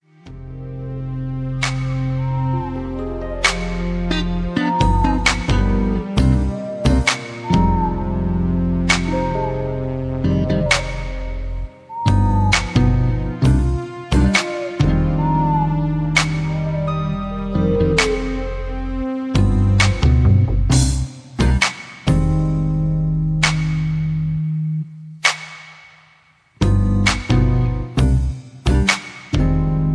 (Version-3, Key-C) Karaoke MP3 Backing Tracks
Just Plain & Simply "GREAT MUSIC" (No Lyrics).